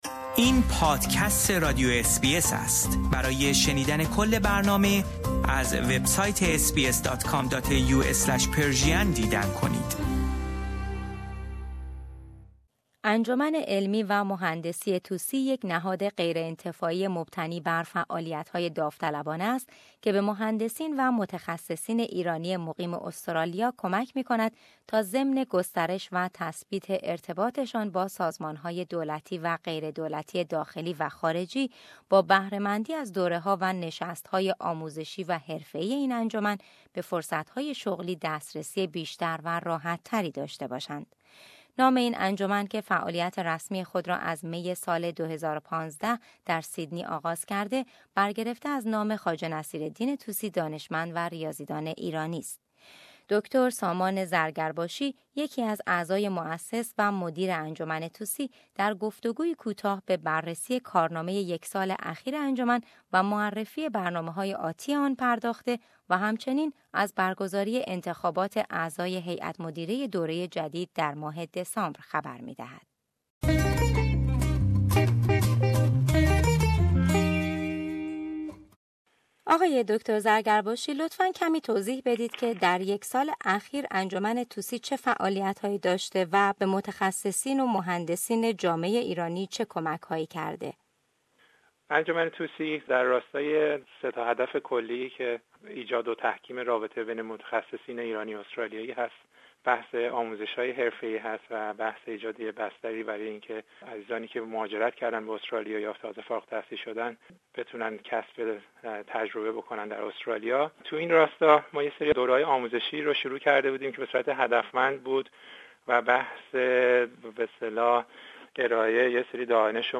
گفت و گویی کوتاه